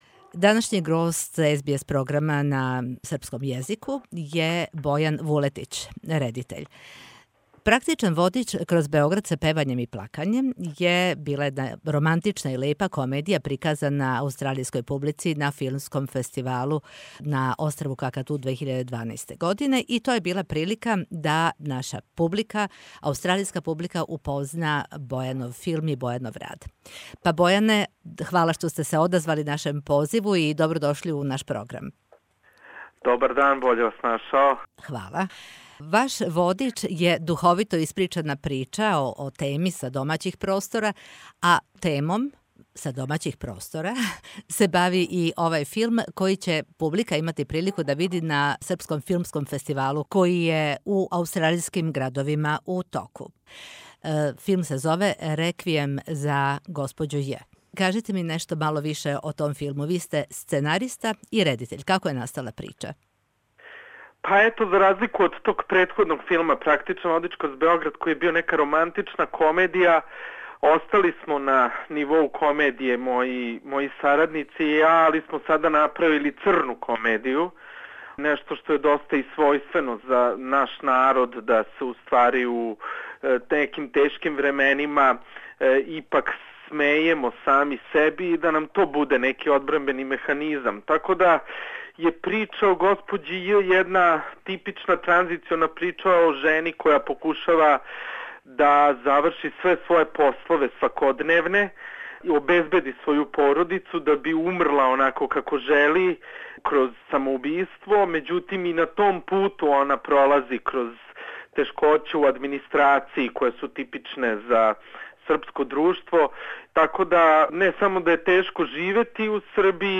У интервјуу за СБС Радио